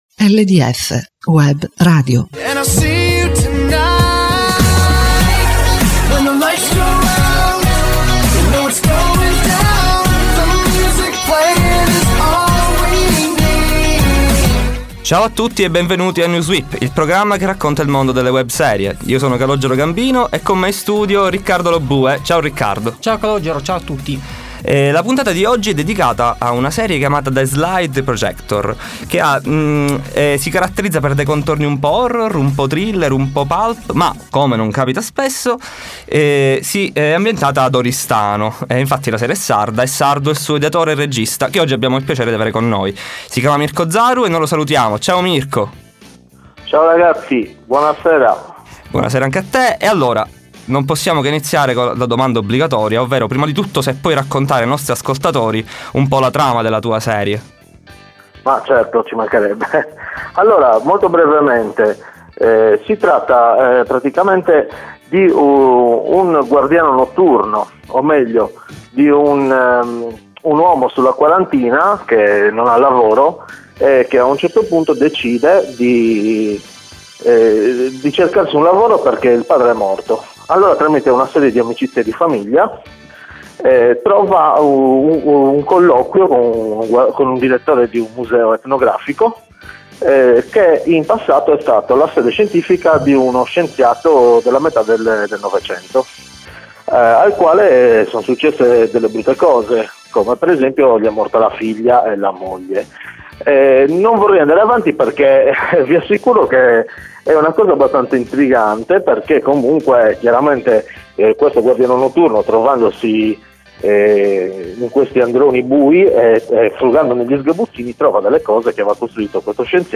Newswip è un talk radiofonico dedicato alle web star, personaggi divenuti famosi grazie a Facebook e YouTube. In ogni puntata viene intervistato un ospite che racconta la sua esperienza, consigli e curiosità con il medium utilizzato.